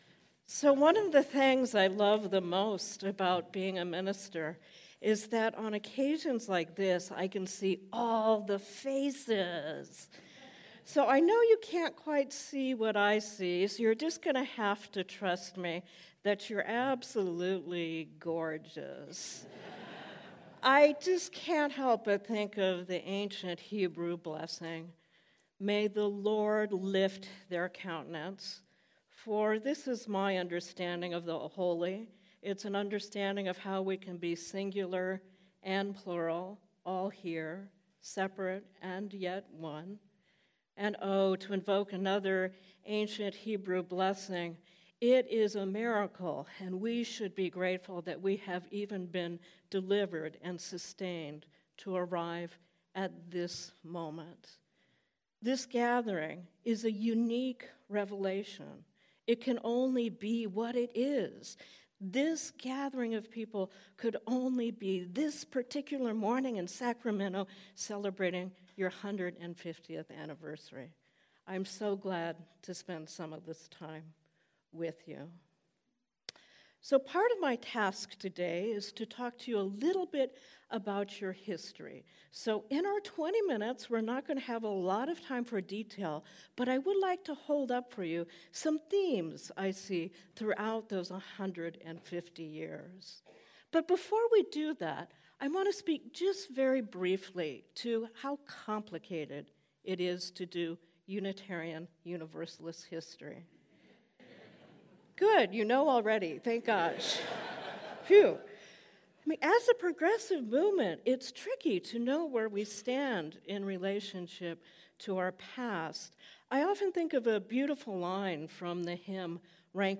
March 4-150th Anniversary Celebration Kickoff Sunday 10:30 Sermon: Reinventing Unitarian Universalism: Sacramento’s Sesquicentennial and Beyond
sermon-launching-our-150th-anniversary-celebration.mp3